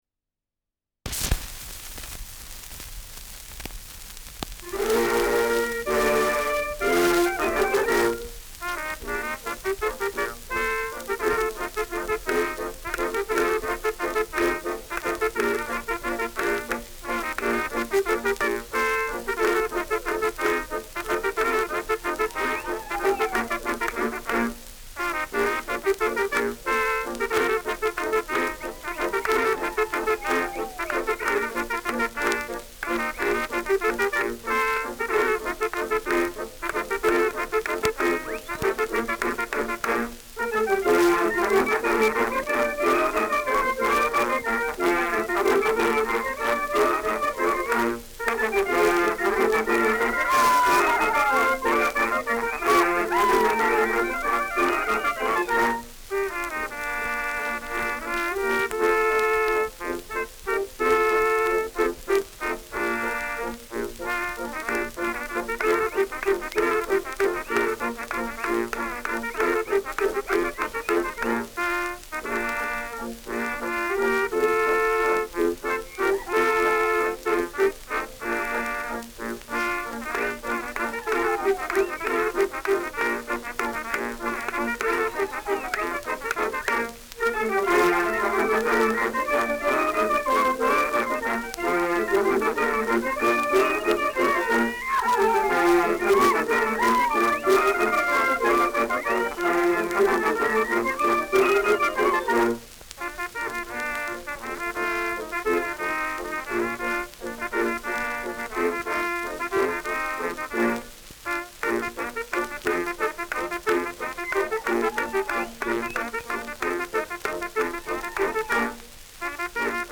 Schellackplatte
Stärkeres Grundrauschen : Erhöhter Klirrfaktor : Durchgehend leichtes Knacken
Militärmusik des k.b. 1. Infanterie-Regiments, München (Interpretation)